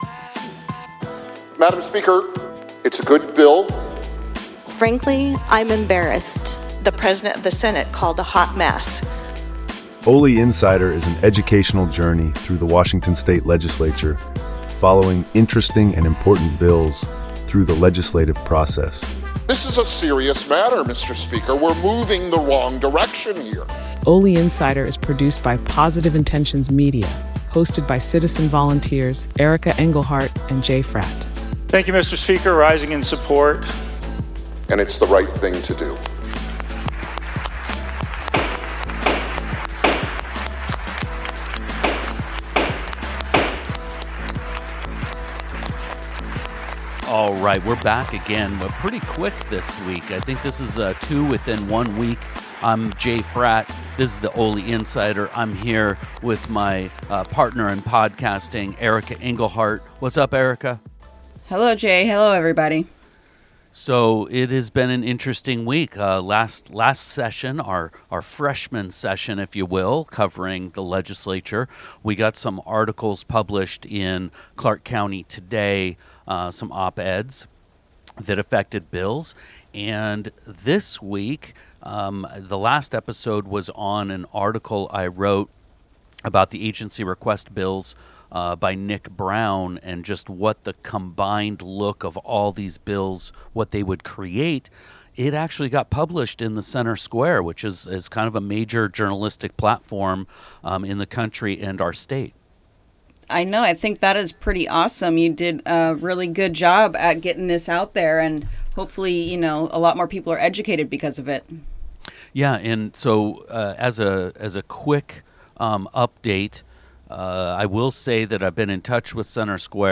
in the Positive Intentions Media studio in Olympia, Washington